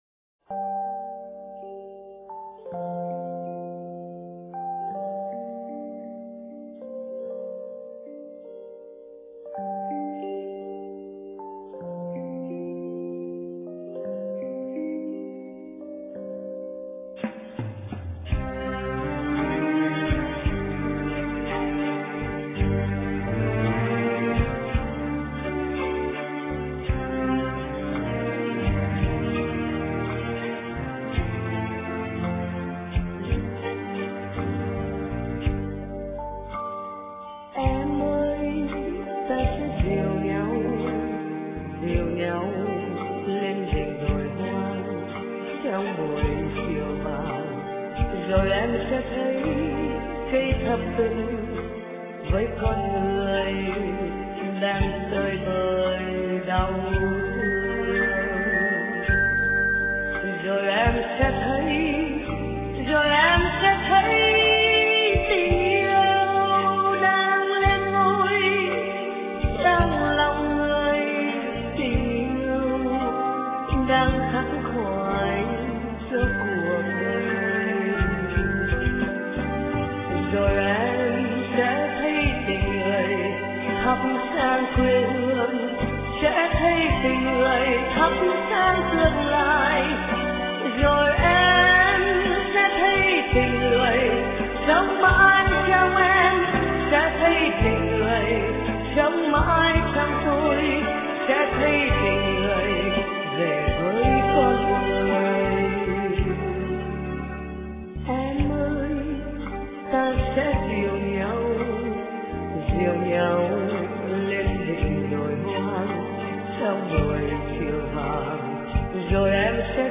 * Thể loại: Ngợi ca Thiên Chúa